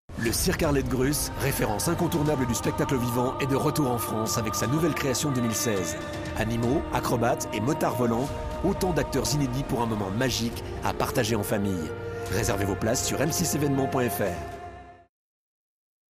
Genre : Voix-off